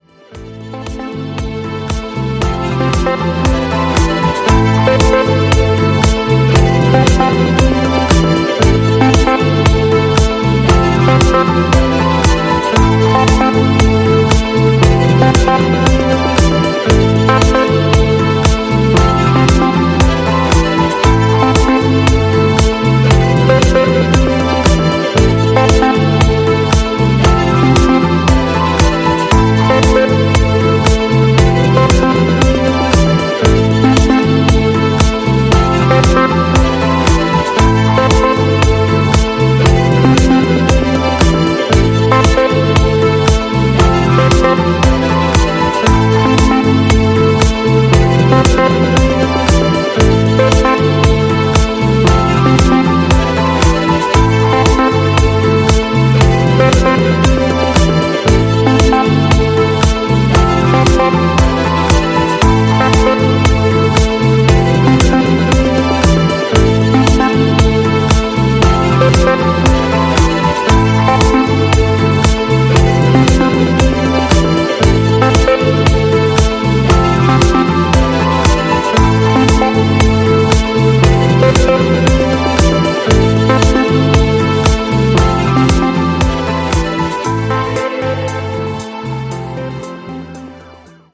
今回は、シカゴスタイルのディープハウスに仕上がったグッドな1枚です！
ジャンル(スタイル) DEEP HOUSE / HOUSE